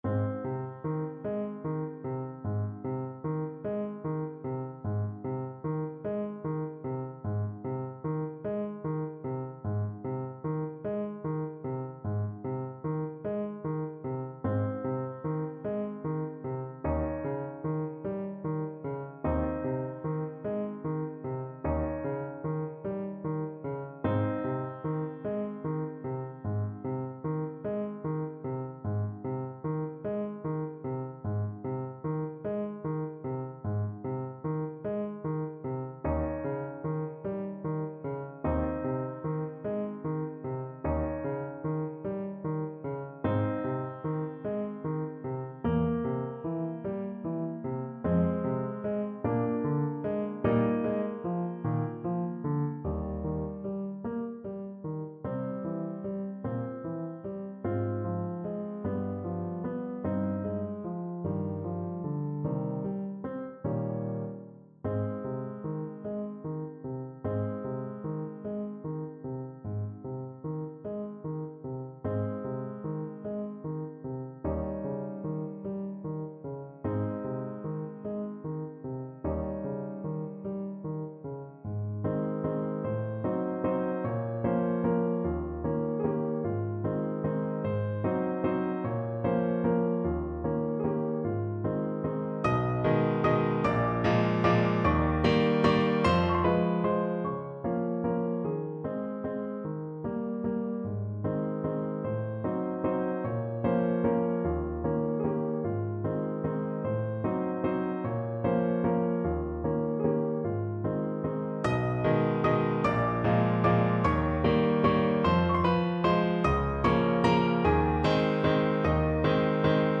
Largo
4/4 (View more 4/4 Music)
Classical (View more Classical Saxophone Music)